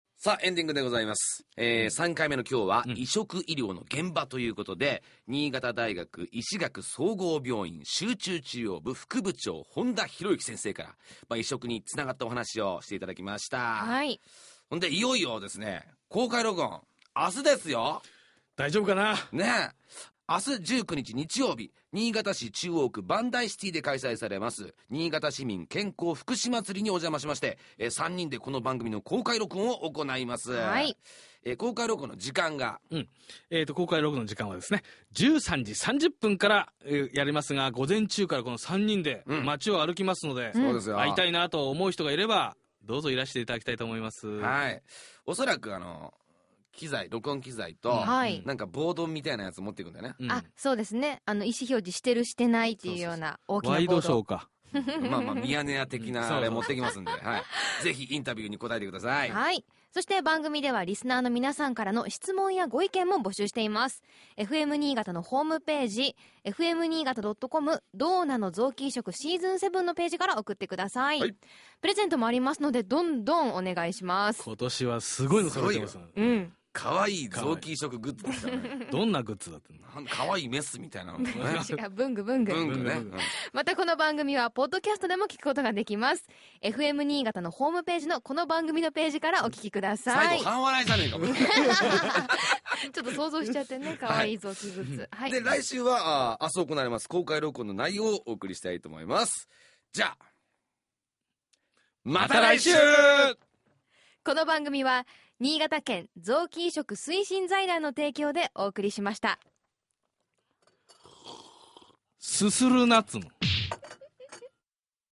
※BGMやリクエスト曲、CMはカットしています。